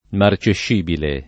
[ mar © ešš & bile ]